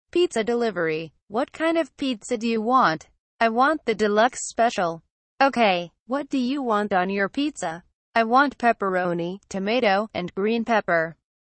Conversation Dialog #2: